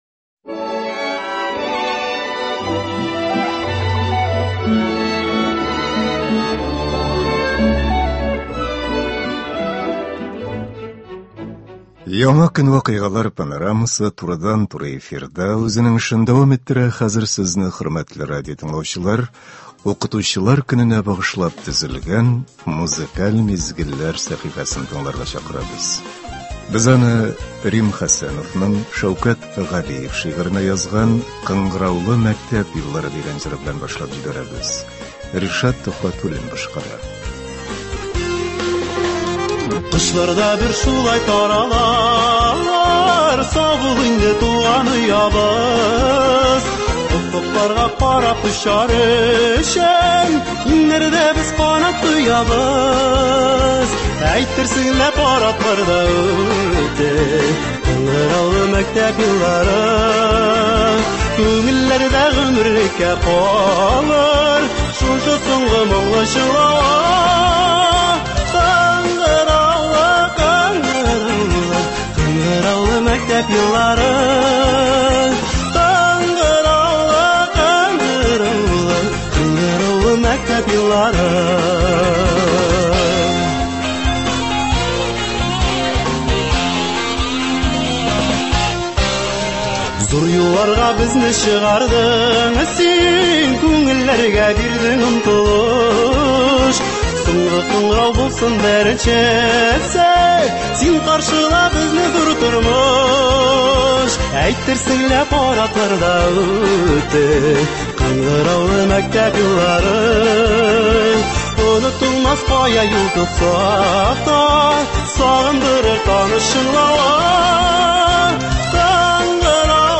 Укытучыларга багышланган концерт.